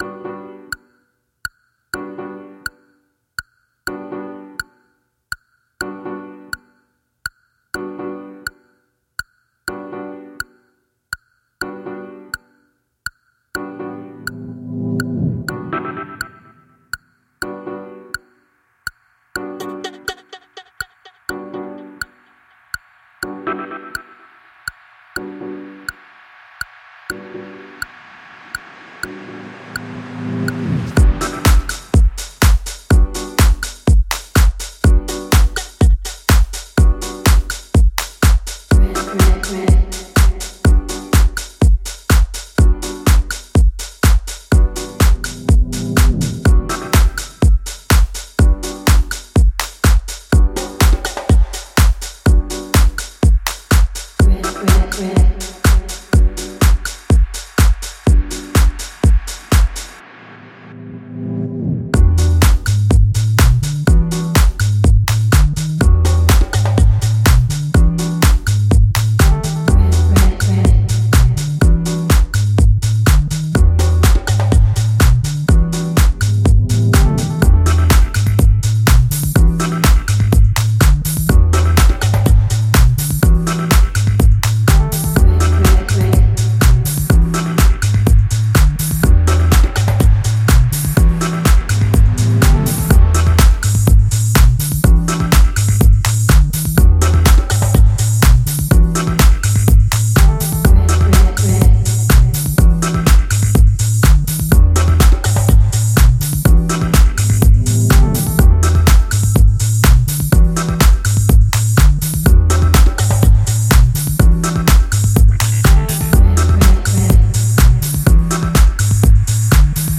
Another fine deep sound